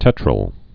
(tĕtrəl)